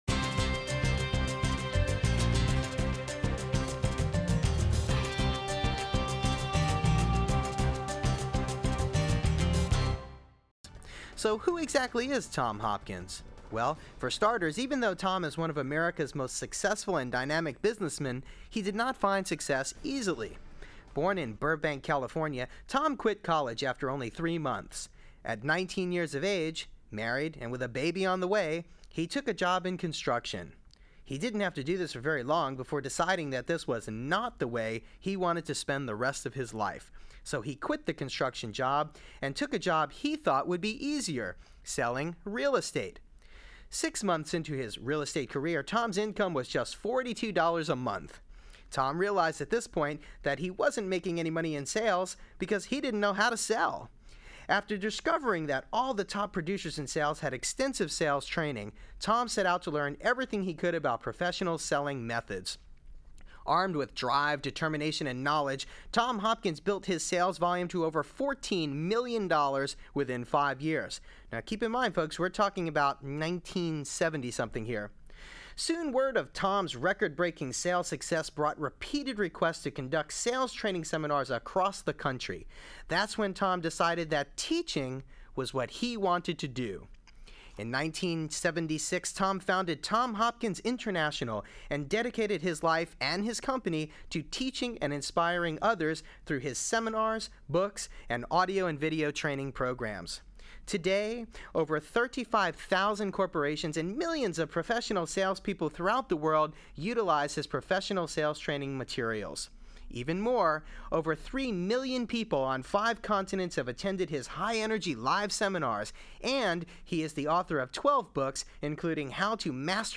Interview with Tom Hopkins – Mastering of Art of Selling